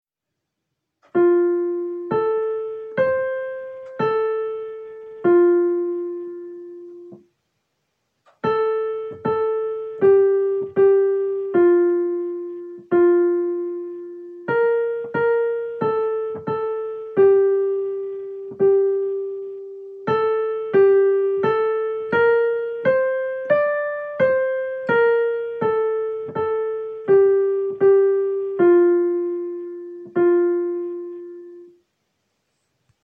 4_kl-diktant_fa_mazhor.mp3